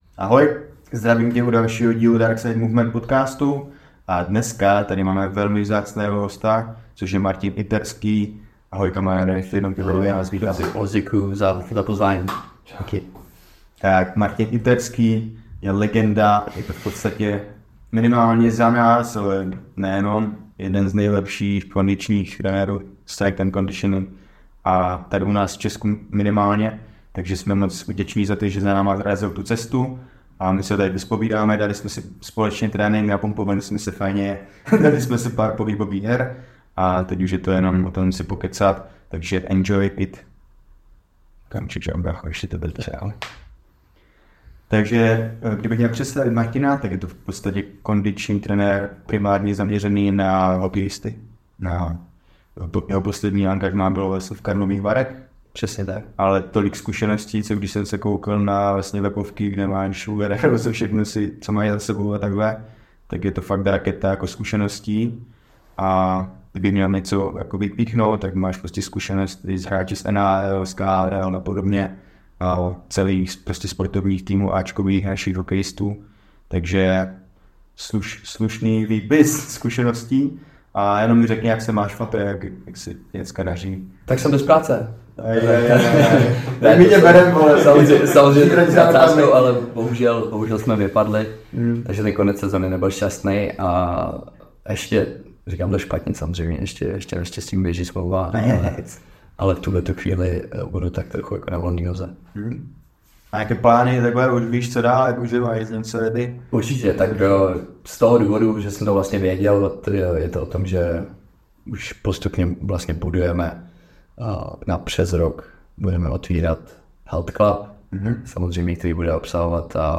proto se moc omlouváme za zhoršenou kvalitu zvuku …